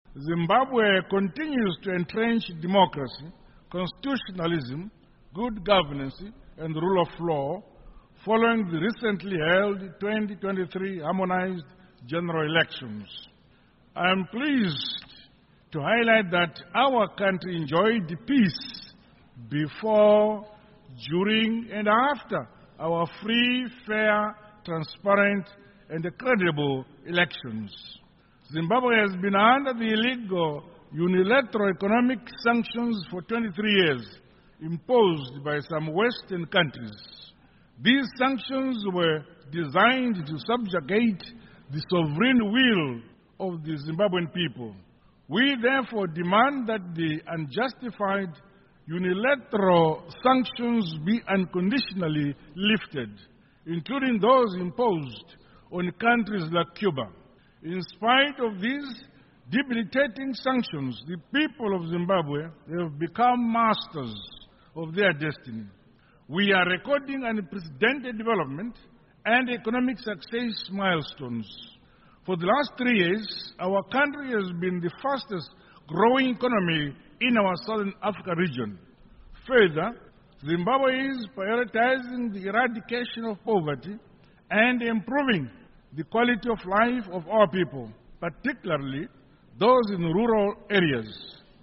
Amazwi kaMongameli Emmerson Mnangagwa